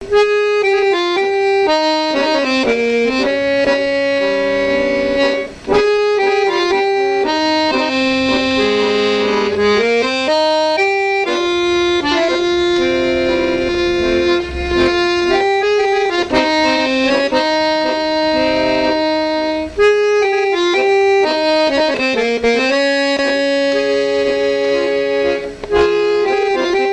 danse : tango
Pièce musicale inédite